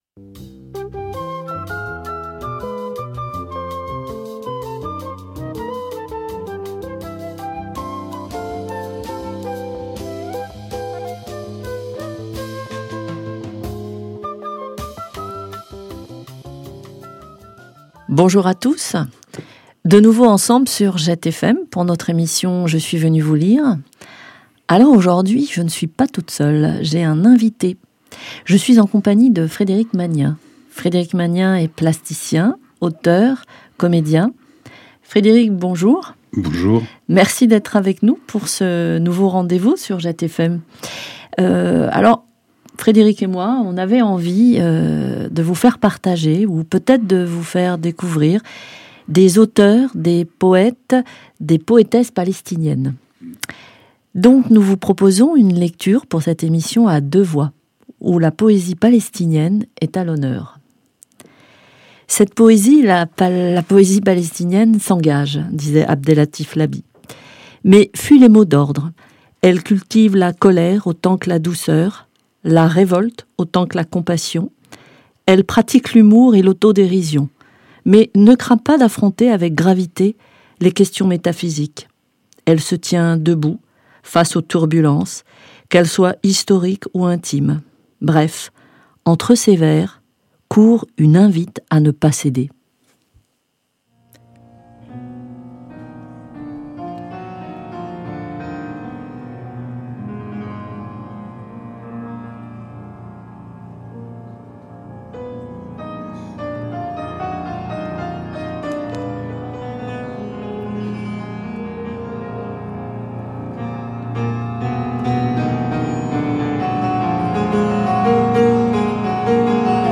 Lecture à deux voix pour une découverte de la poésie palestinienne.